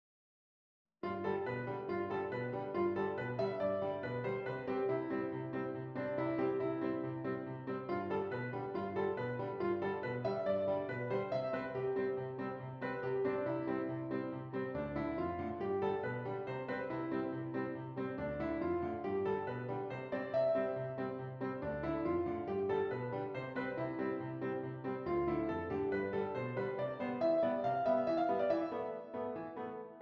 Does Not Contain Lyrics
A Minor
Allegro